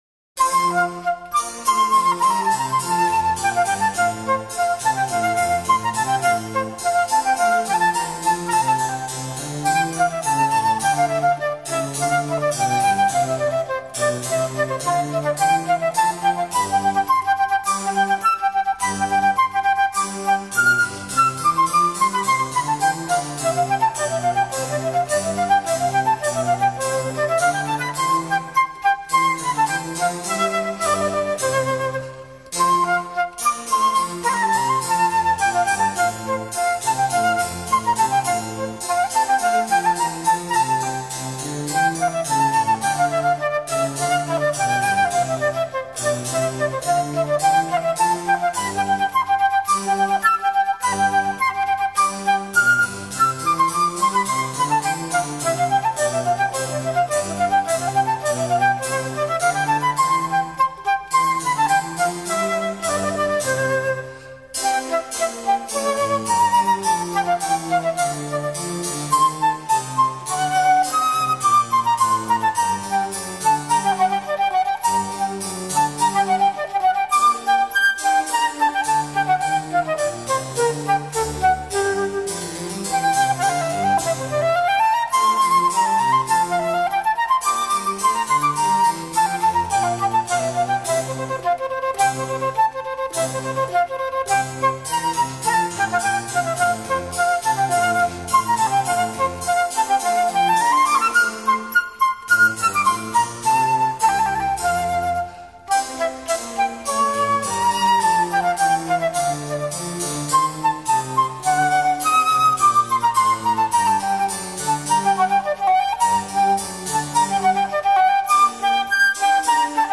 Género:  Instrumental